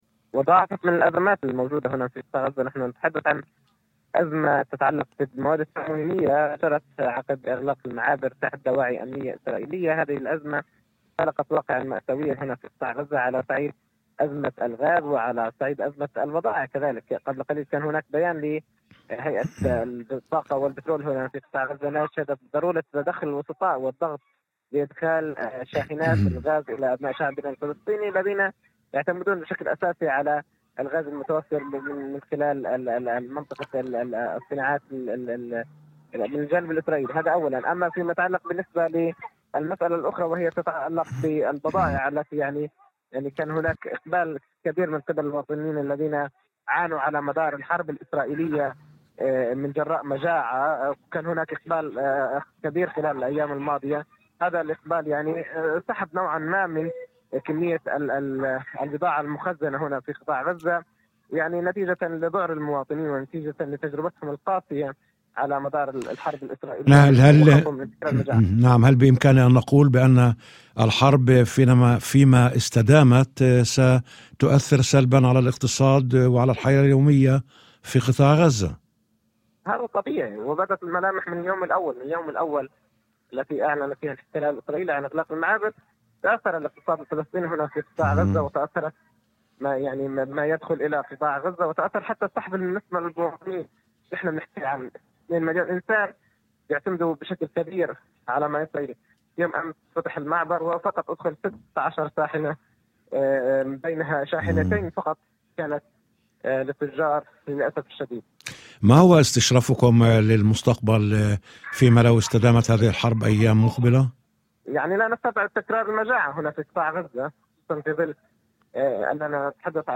وأضاف في مداخلة هاتفية ضمن برنامج "يوم جديد" على إذاعة الشمس، أن الأيام الماضية شهدت إقبالًا كبيرًا من المواطنين على شراء السلع، في ظل مخاوف من تفاقم الوضع، ما أدى إلى سحب كميات كبيرة من المخزون المتوفر في الأسواق.